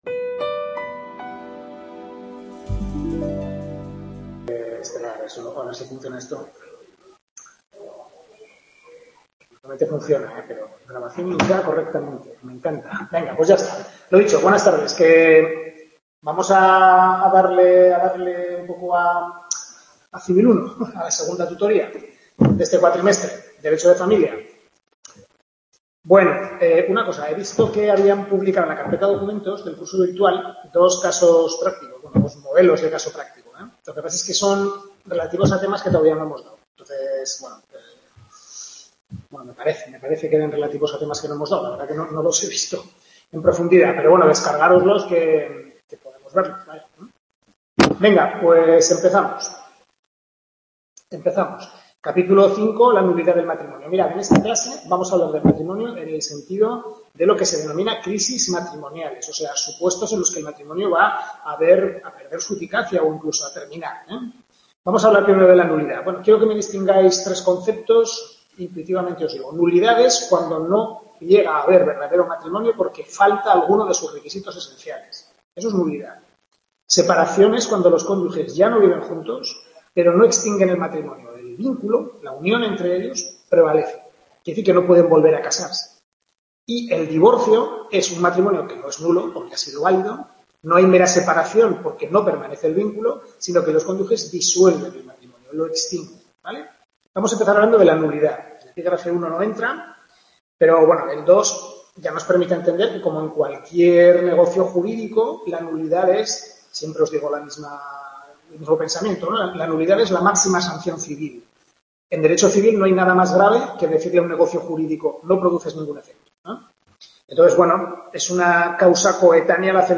Tutoría de Civil II, centro UNED Calatayud